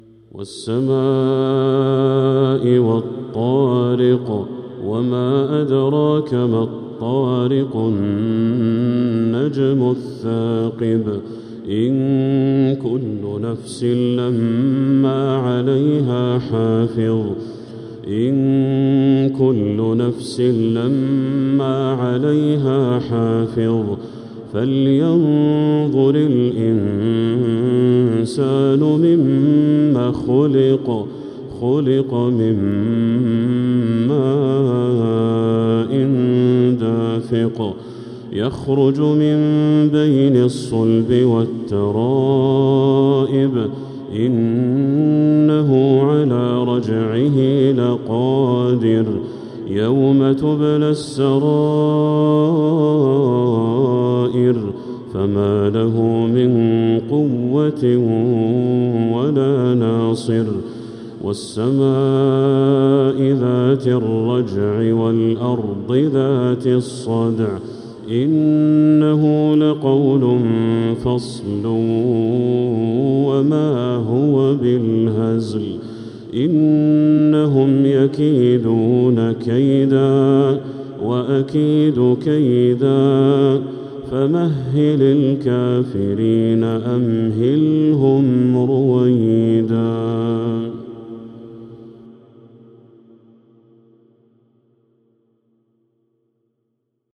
سورة الطارق كاملة | رجب 1446هـ > السور المكتملة للشيخ بدر التركي من الحرم المكي 🕋 > السور المكتملة 🕋 > المزيد - تلاوات الحرمين